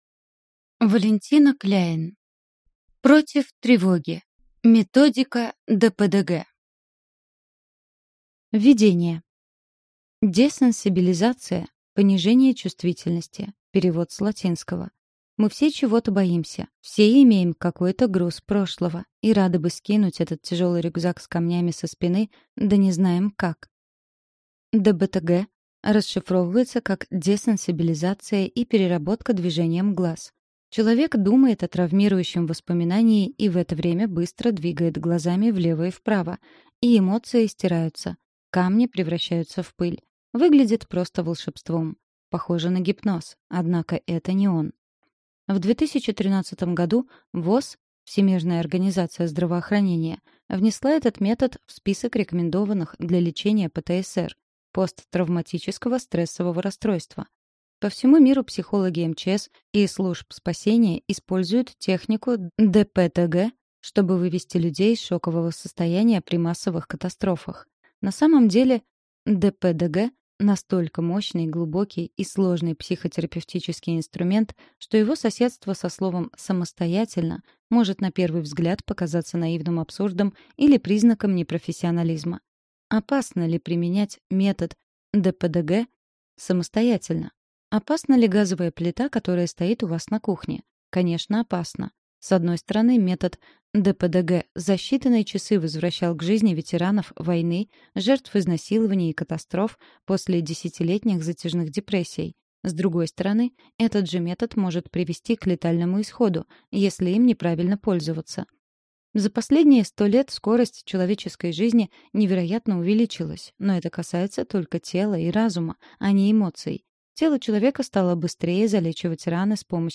Аудиокнига Против тревоги: методика ДПДГ | Библиотека аудиокниг